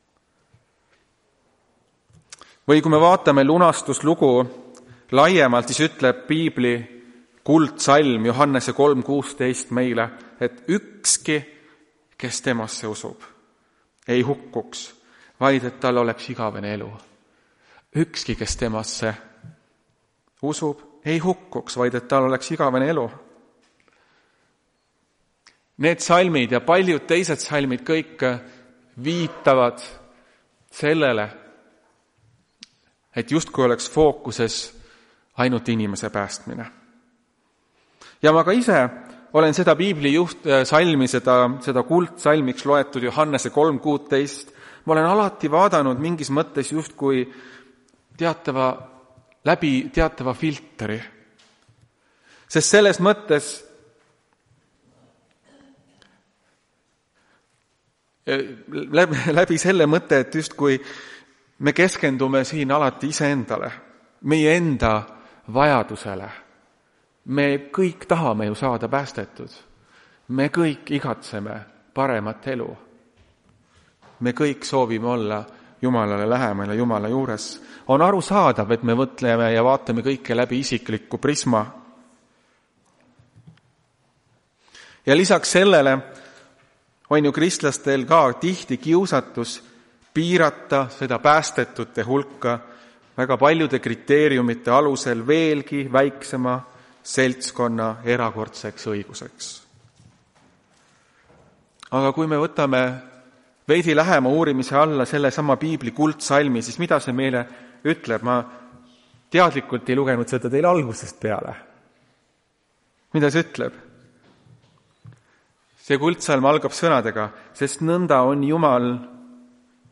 Tartu adventkoguduse 10.05.2025 hommikuse teenistuse jutluse helisalvestis.